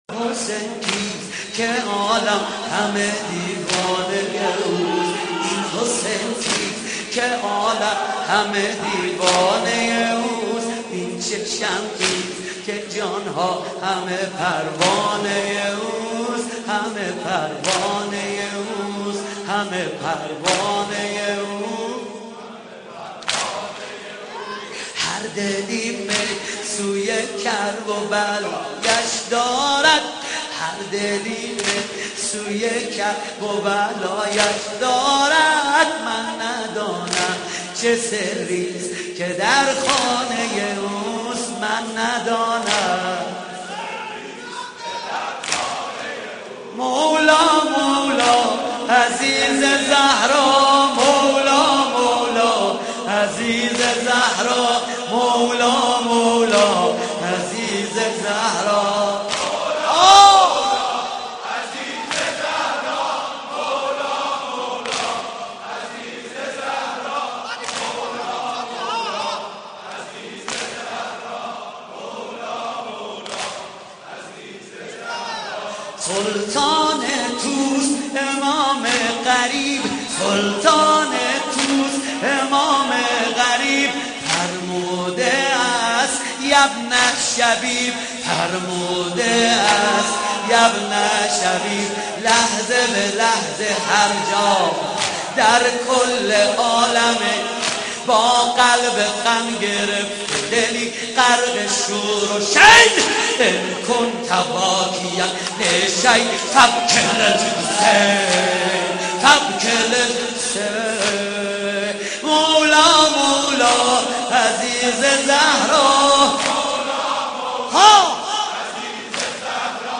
محرم 88 - سینه زنی 4
محرم-88---سینه-زنی-4